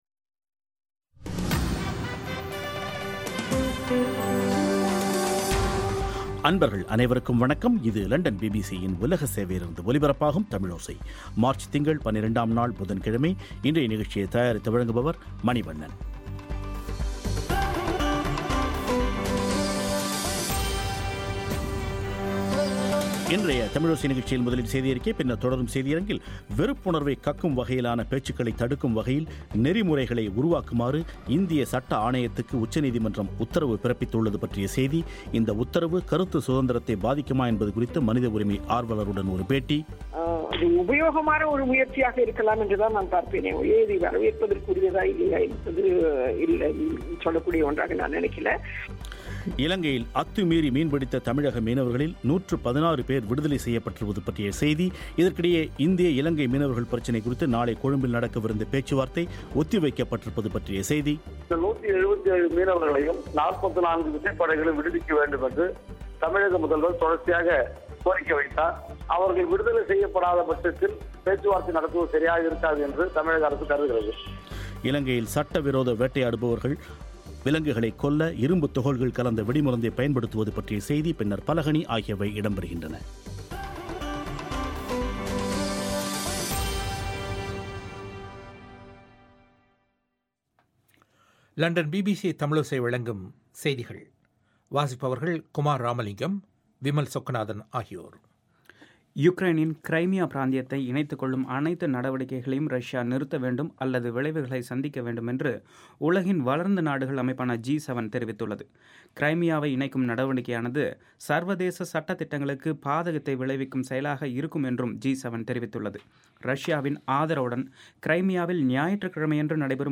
இன்றைய தமிழோசை நிகழ்ச்சியில் வெறுப்புணர்வைக் கக்கும் வகையிலான பேச்சுக்களை தடுக்கும் வகையில் நெறிமுறைகளை உருவாக்குமாறு இந்திய சட்ட ஆணையத்துக்கு உச்சநீதிமன்றம் உத்தரவு பிறப்பித்துள்ளது பற்றிய செய்தி இந்த உத்தரவு கருத்து சுதந்திரத்தை பாதிக்குமா என்பது குறித்து மனித உரிமை ஆர்வலருடன் ஒரு பேட்டி